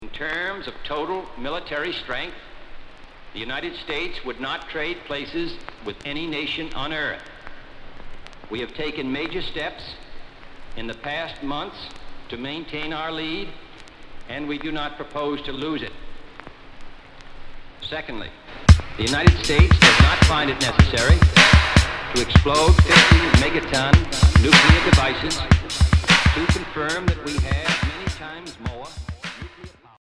Cold wave Unique 45t